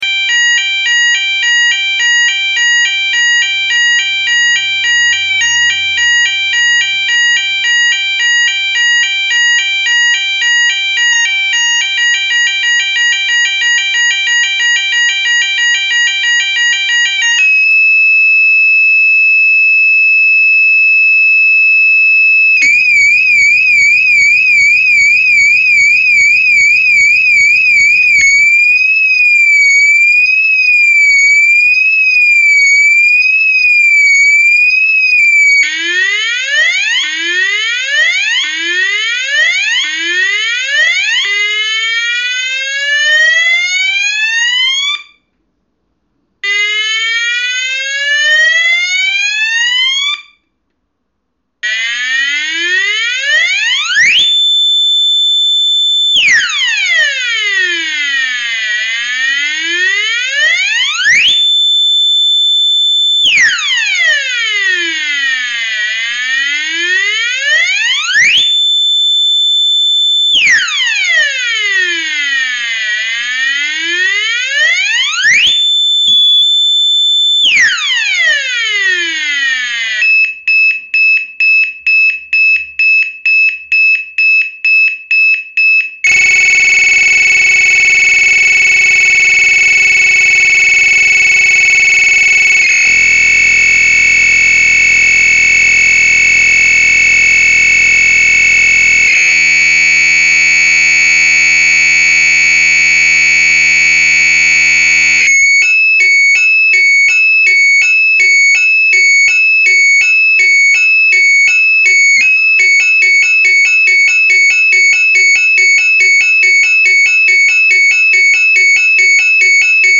Sirene Alta Potência | Renglan
Sirene eletrônica do tipo corneta em chapa, na cor preta e Branca, com base fixa.
Consumo: 12 VCC 3a ............. 24VCC 1,5a - 110 Vca 0,4 A 220 Vca 0,2 A Nível sonoro 118 dB.
*Tipo intermitente , bitonal e 2 varredura de freqüência
amostra sons BT-7 Multitok.MP3